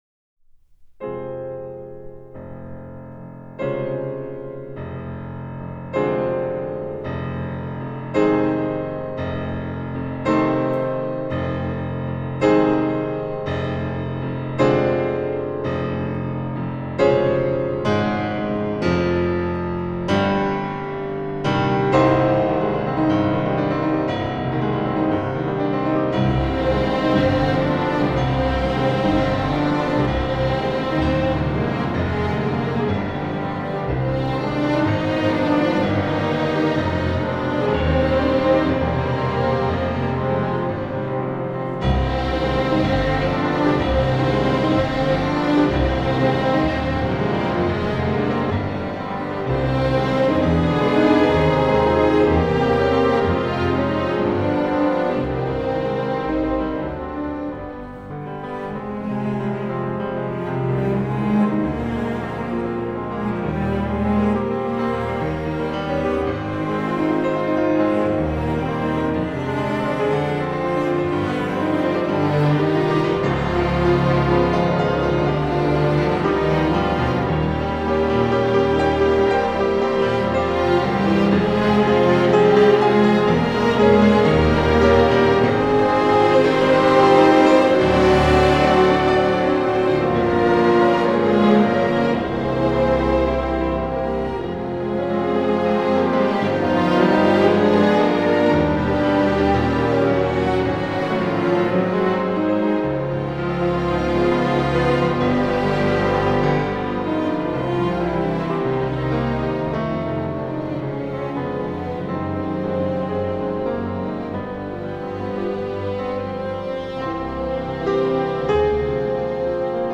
05.Piano Concerto No. 2 in C minor Op. 18 yori, First Movement kara.mp3